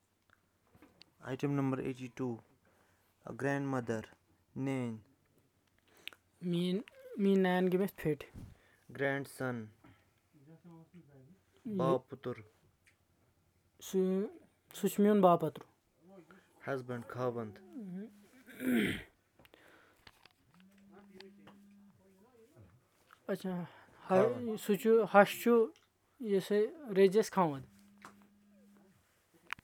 Elicitation of words about kinship and relation terms